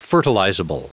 Prononciation du mot fertilizable en anglais (fichier audio)
fertilizable.wav